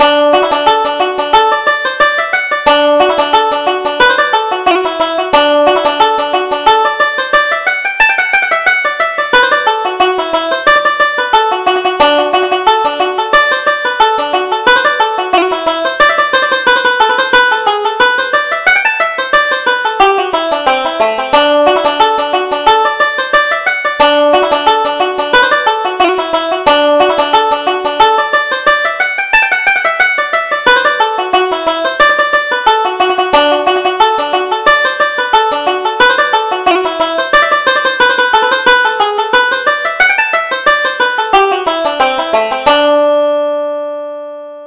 Reel: Farewell to Erin